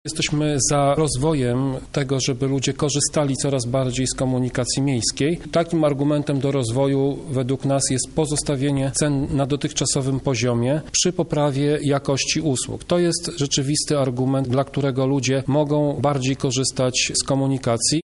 Taką opinię podziela także radny PiS Tomasz Pitucha.